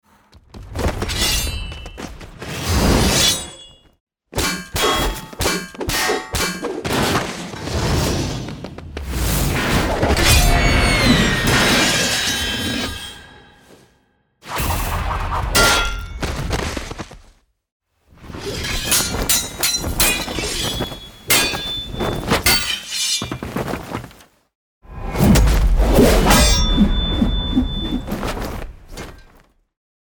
游戏音效[冷兵器] – 深圳声之浪潮文化传播有限公司
【冷兵器】是游戏中的冷兵器音效，是决定战斗沉浸体验的关键。
为打造逼真音效，需融合声学原理与艺术创作，精准考量兵器造型、材质、尺寸及受力方向，细致捕捉出鞘、入鞘、挥舞、碰撞、摩擦等动态细节。
我们团队以真实还原为目标，精心打磨刀剑在不同情境下的音色，无论是剑刃破空的轻盈，还是与其他材质相撞的厚重，都力求为真实，让玩家身临其境感受冷兵器交锋的魅力。